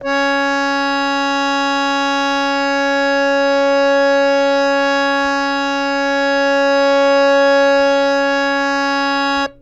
harmonium